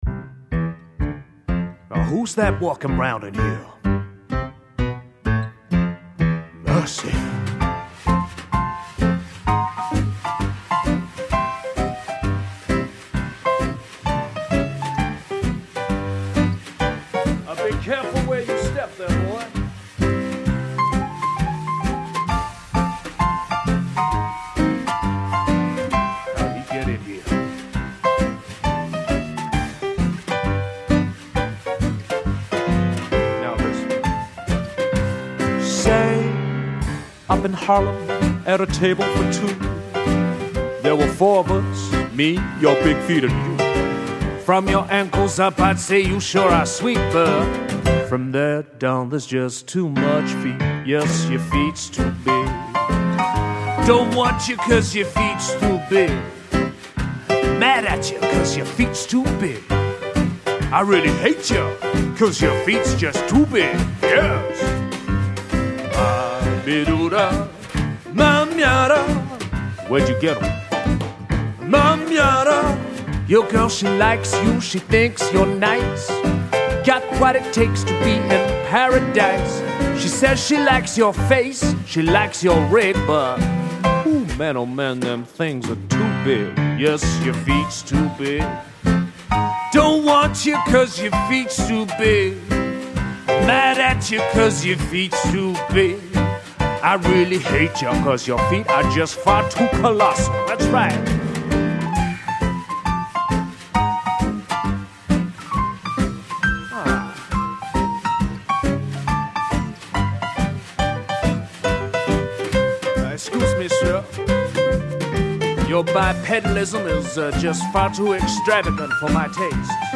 Vocals / Guitar, Piano / Clarinet, Percussion / Drums